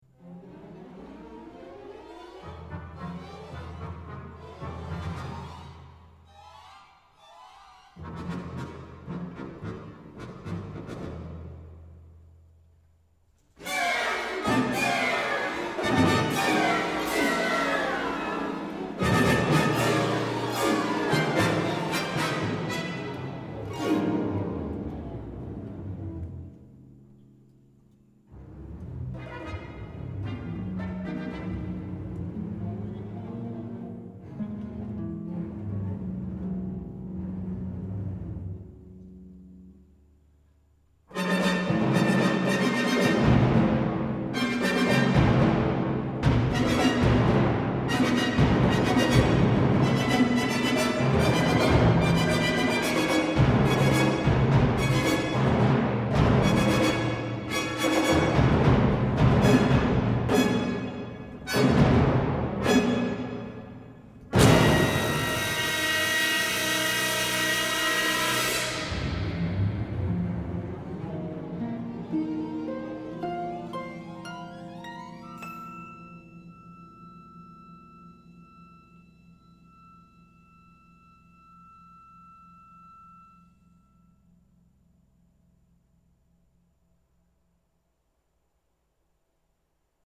Concerto for Orchestra 管弦樂協奏曲 32 minutes